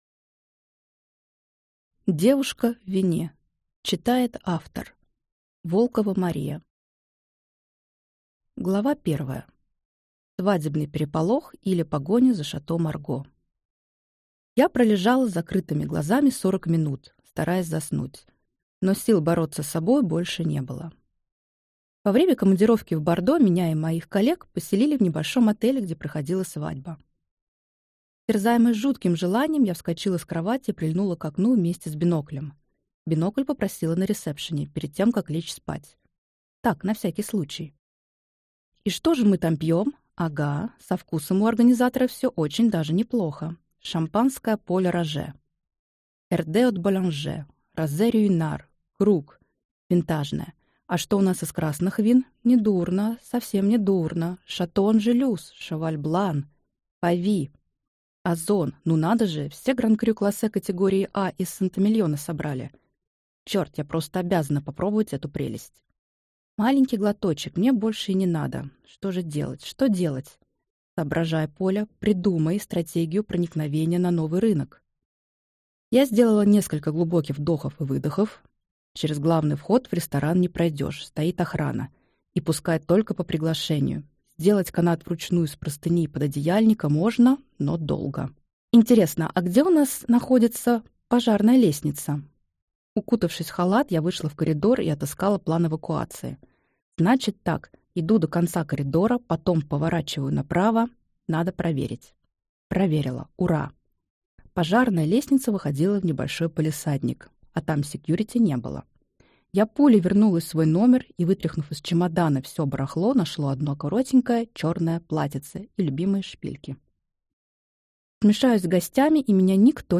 Аудиокнига Девушка в вине | Библиотека аудиокниг